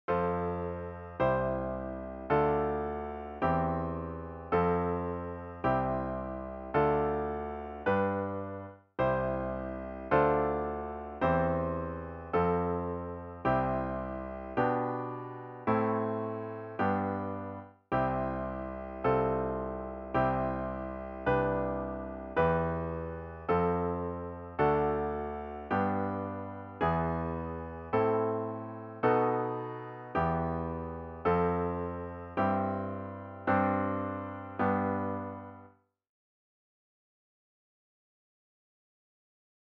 The examples are in C major/A minor, but are of course transposable to any key.
A new browser window will open, and you’ll hear each progression in basic long-tones.
Listen VERSE:  F  C  Dm  C/E  F  C  Dm  G|| CHORUS:  C  Dm7  C/E  F  C  G/B  Am  G  || BRIDGE:  C  F/C  C  G/C  Em  F  D  G  E  Am  B7  Em  F  C/G  Gsus4  G